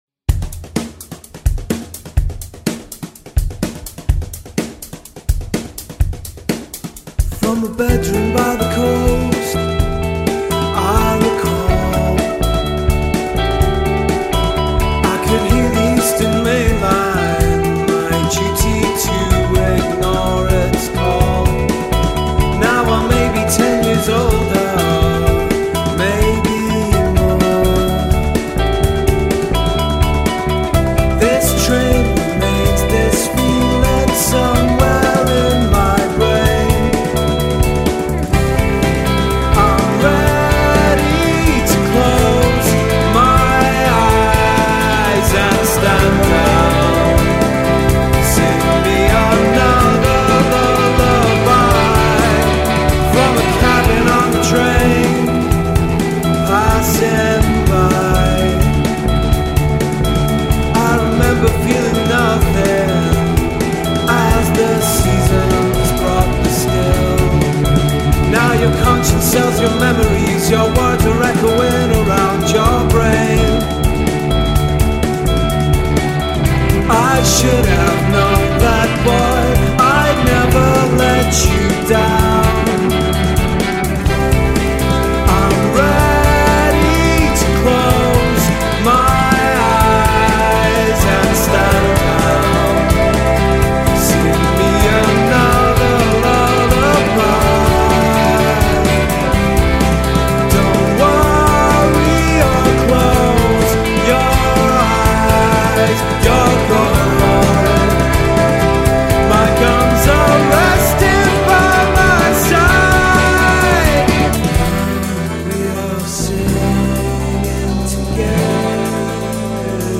Edinburgh based indie rock band